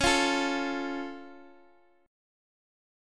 C#+ Chord
Listen to C#+ strummed
Csharp-Augmented-Csharp-x,x,x,6,6,5-1-down-Guitar-Standard-2.m4a